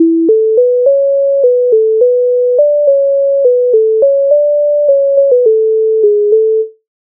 MIDI файл завантажено в тональності A-dur
Чого, Івасю змарнів Українська народна пісня з обробок Леонтовича с. 153
Ukrainska_narodna_pisnia_Choho__Ivasyu_zmarniv.mp3